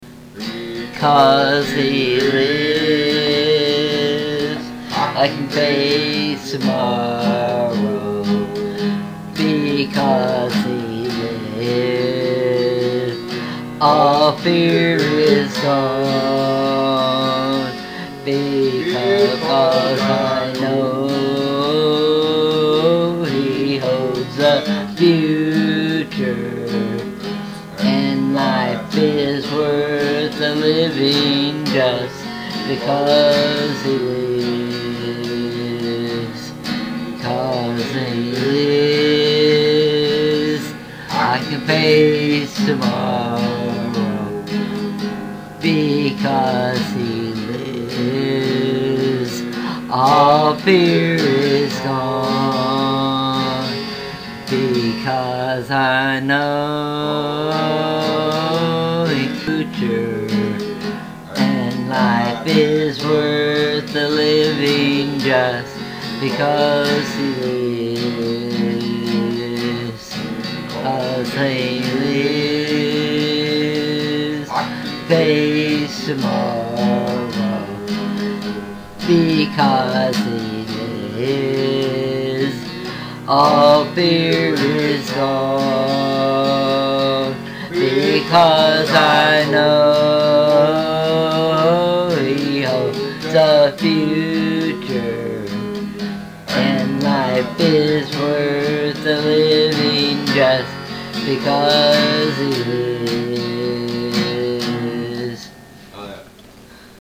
Tags: RELIGION SINGING SERMONS